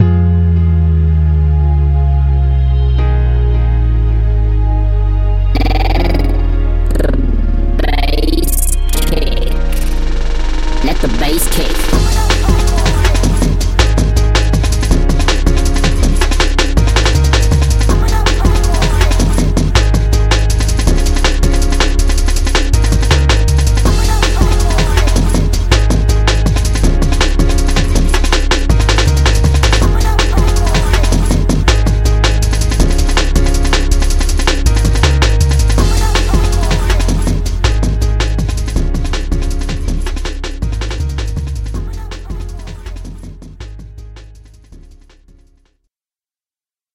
Here’s a breif example of a jungle track making use of the combinator patch demonstrated in this tutorial: